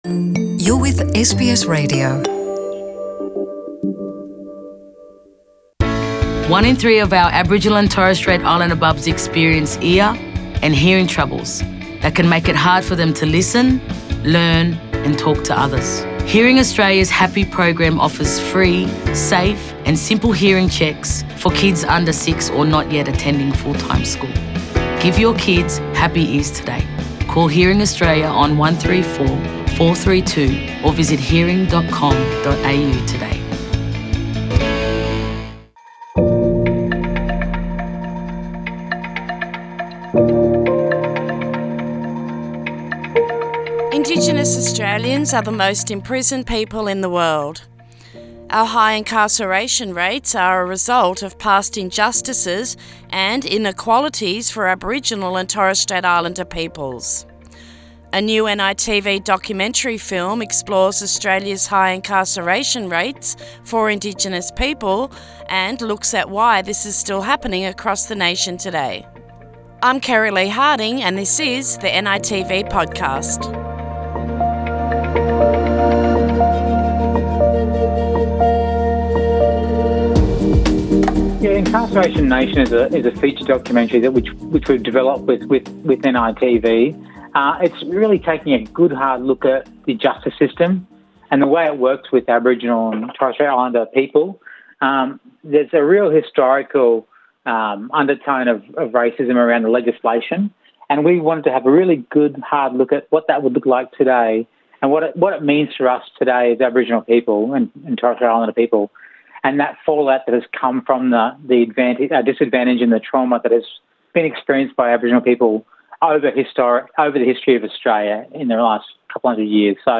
In this episode of the NITV Podcast series we explore the new NITV documentary film Incarceration Nation. Interviews